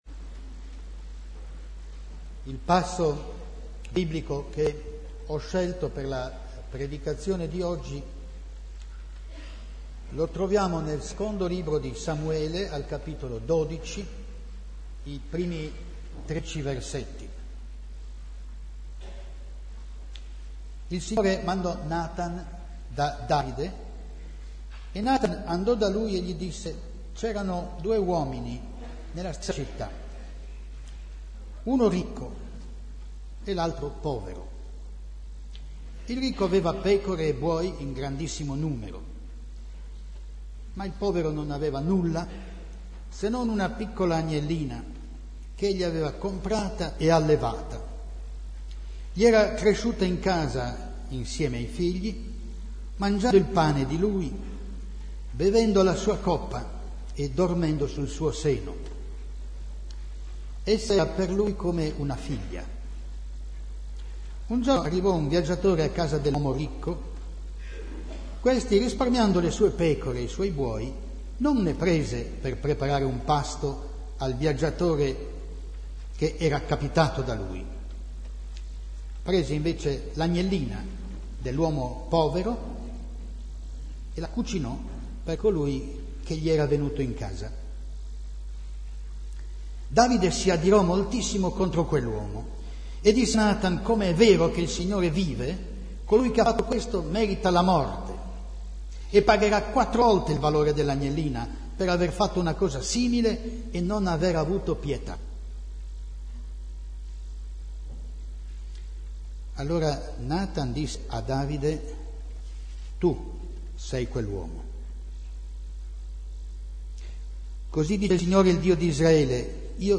culto_si2007.mp3